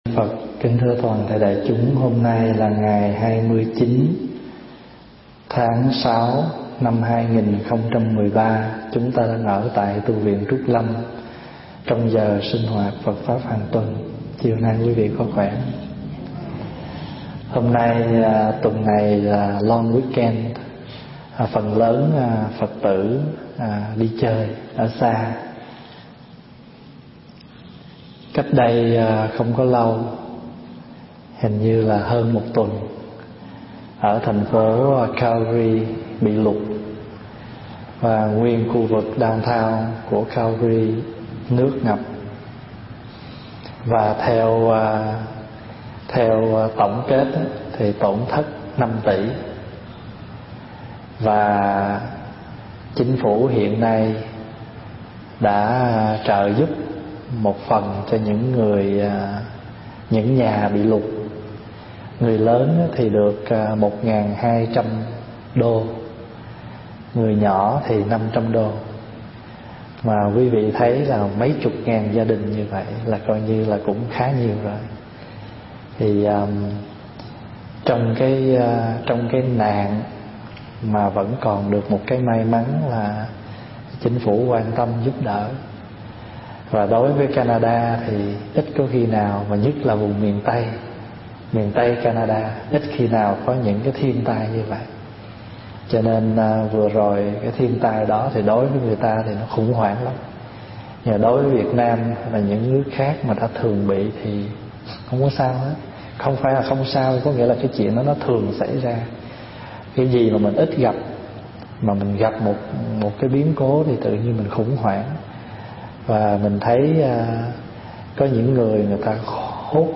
thuyết giảng tại Tu Viện Trúc Lâm, Canada